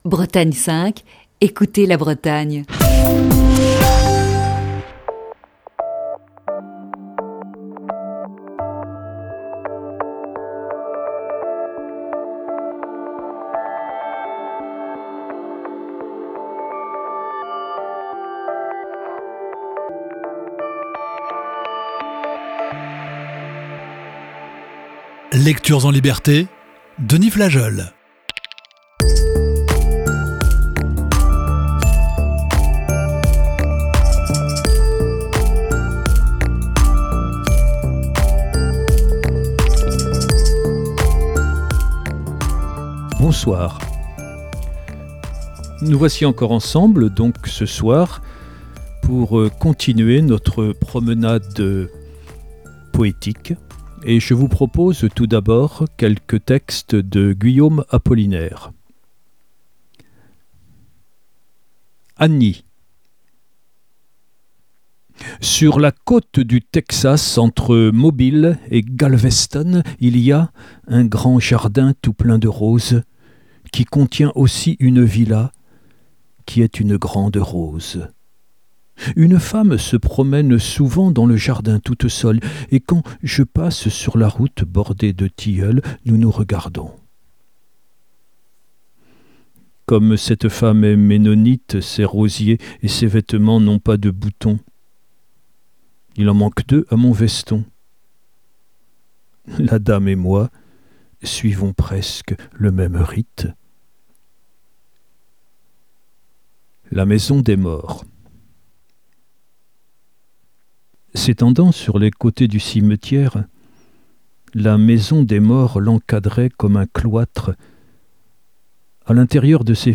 nous propose d'écouter de la poésie. Ce mercredi soir, quelques textes de Guillaume Apollinaire et passages d'un texte de Charles Juliet "L'inattendu".